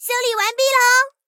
SU-76修理完成提醒语音.OGG